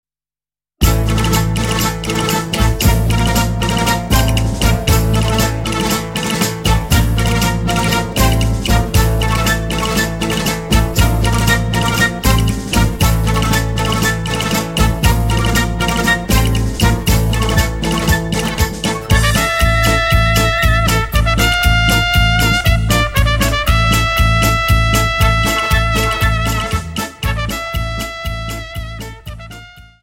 Dance: Paso Doble 59 Song